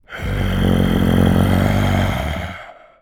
Male_Low_Snarl_01.wav